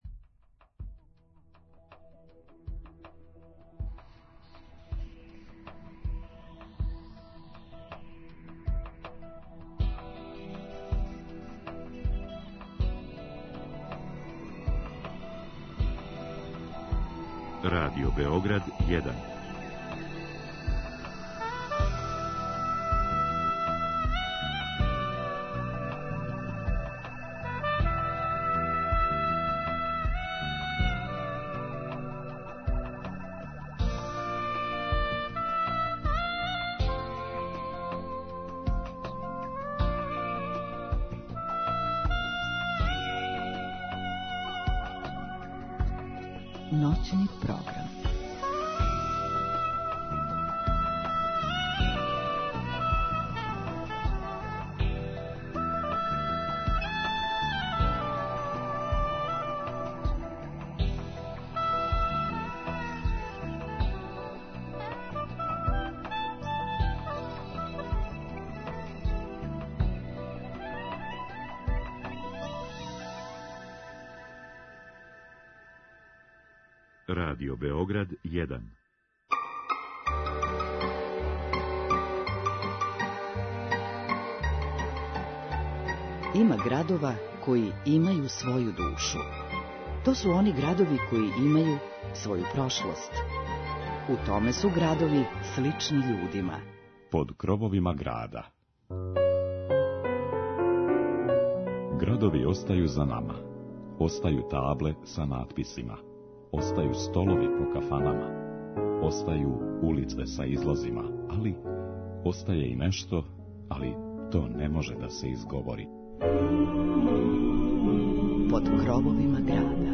Ове ноћи говорићемо о градовима Топличког округа, са посебним освртом на Прокупље, његову историју и музичке уметнике тог града. Имаћете прилику да чујете разговор са врхунским солистом на кларинету